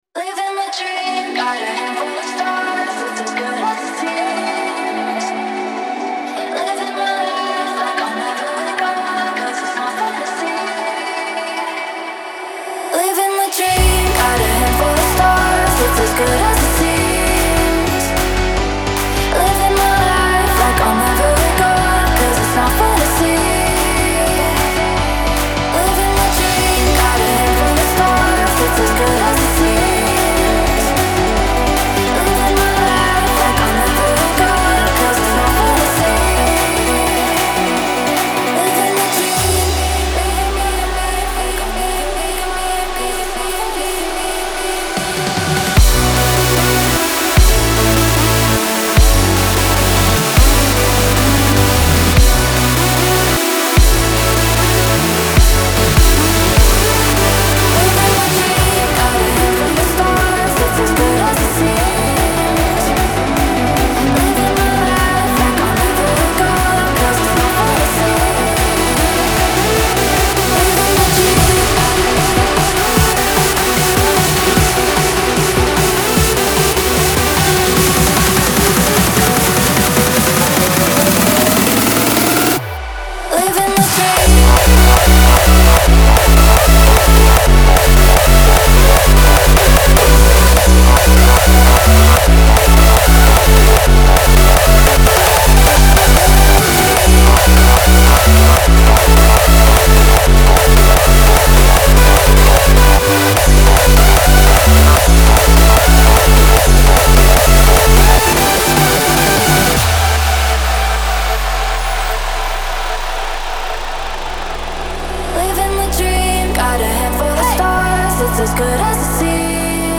• Жанр: Dance, Hardstyle